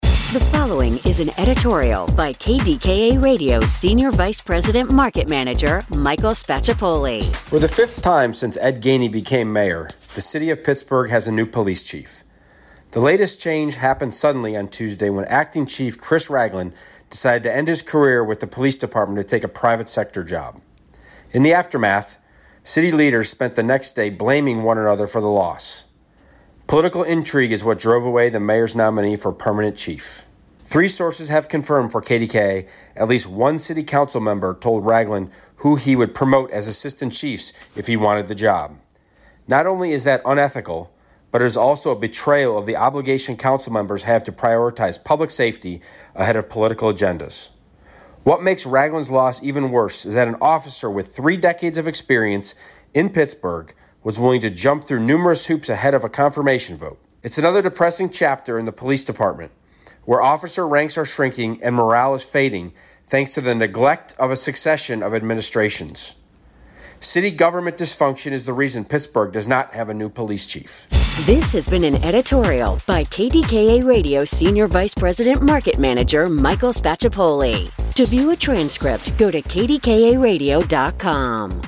KDKA Radio On-Demand - Why Pgh Doesn't Have New Police Chief: KDKA Radio Editorial